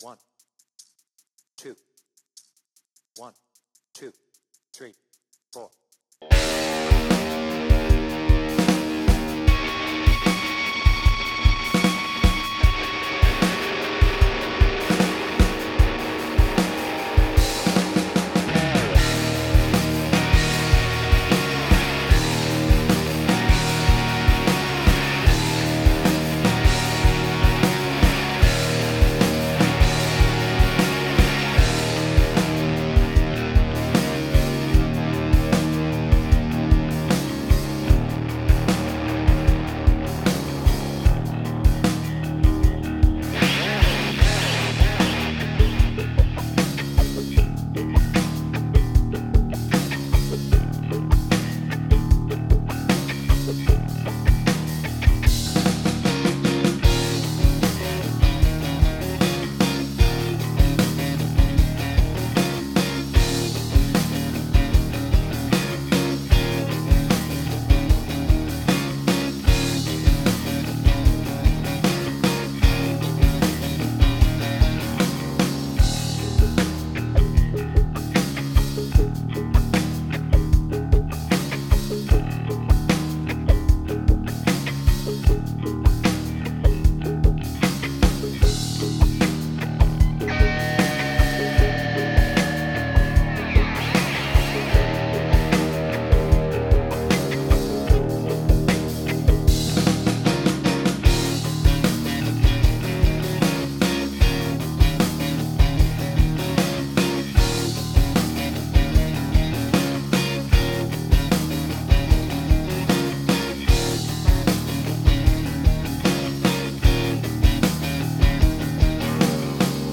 BPM : 76
Tuning : Eb